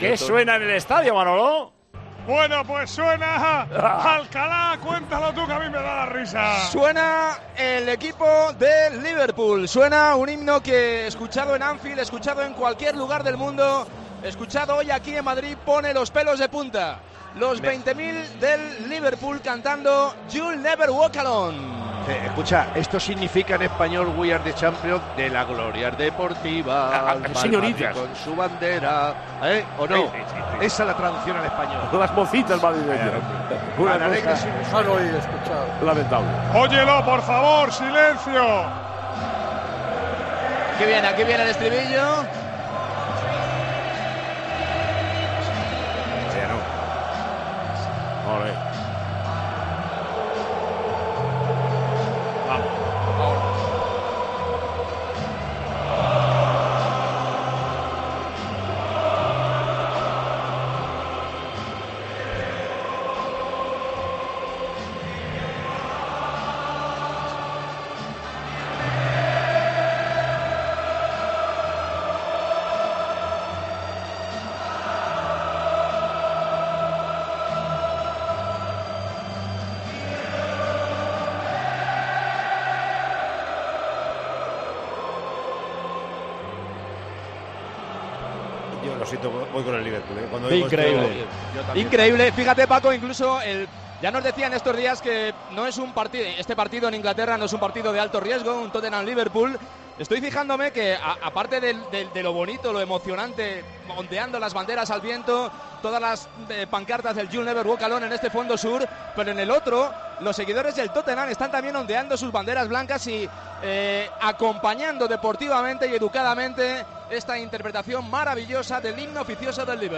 Uno de los himnos más famosos del fútbol también se escuchó en la previa de la Final de la Champions.